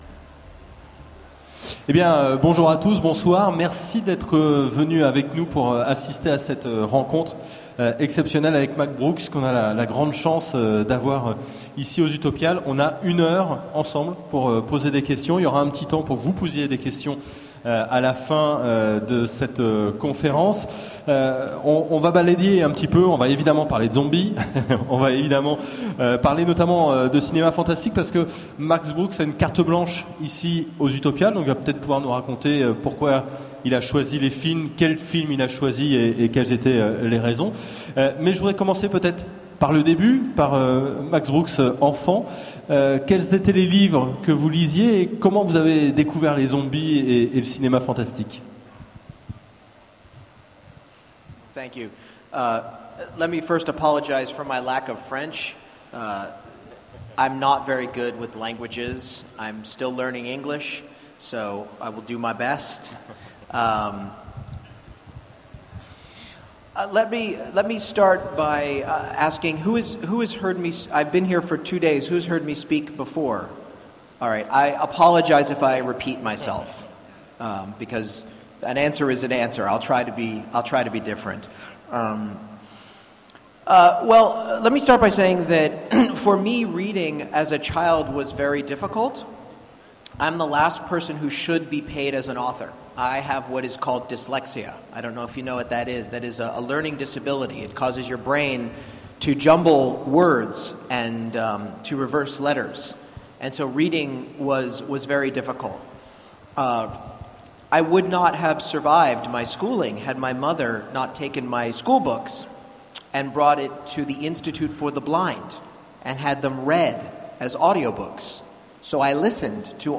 Utopiales 13 : Conférence Rencontre avec Max Brooks
- le 31/10/2017 Partager Commenter Utopiales 13 : Conférence Rencontre avec Max Brooks Télécharger le MP3 à lire aussi Max Brooks Genres / Mots-clés Rencontre avec un auteur Conférence Partager cet article